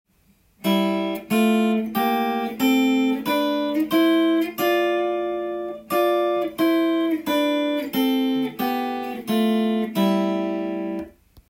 6度ハモリでスケールを弾いたTAB譜
３弦始まり